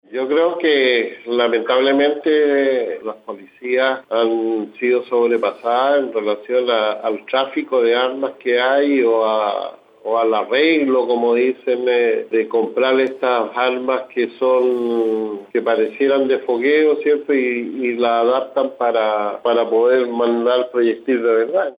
En conversación con Radio Bío Bío, el concejal de Valparaíso y presidente de la Comisión de Seguridad del Concejo Municipal porteño, Dante Iturrieta, señaló que la policía se ha visto sobrepasada en el control de las armas.